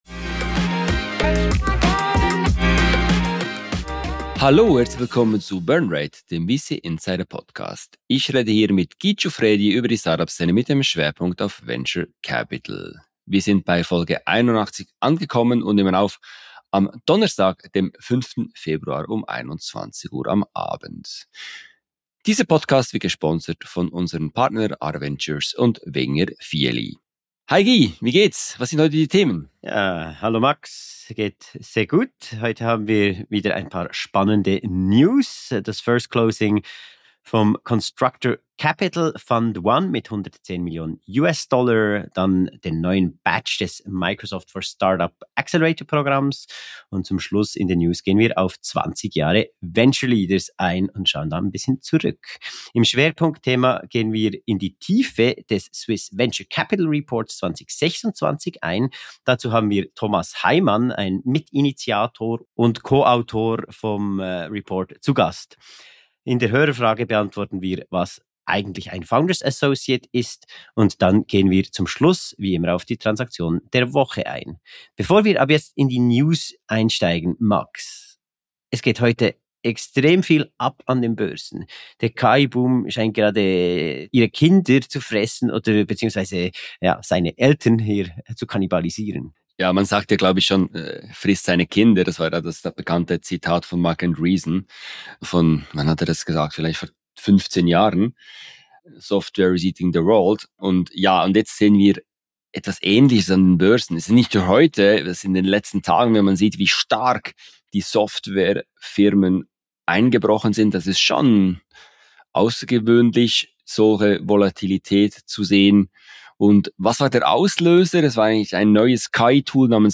inklusive Interview